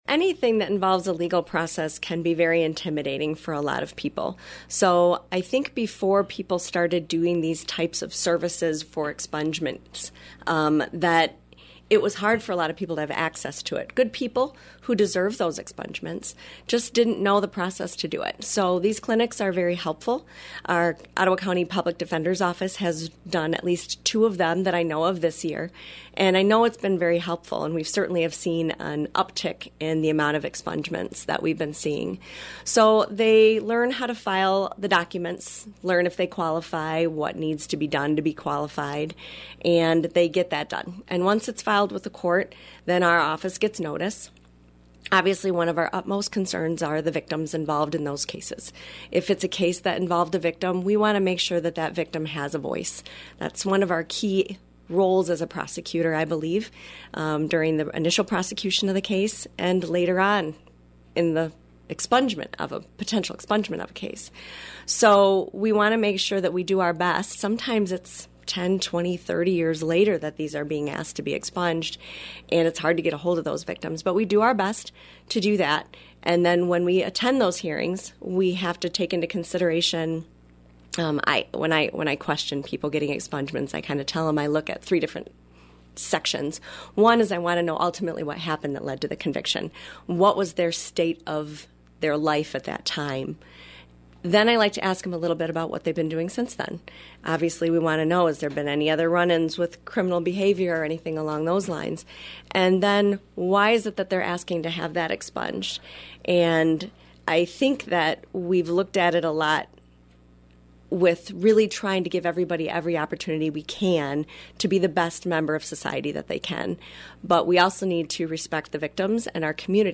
explained in an appearance on “WHTC Talk of the Town” last week how the “Clean Slate” law passed three years ago has helped make the expungement process smoother.